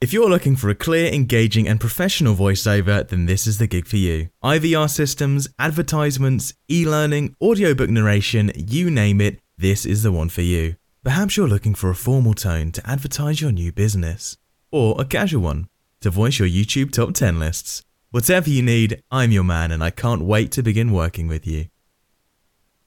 外籍英式英语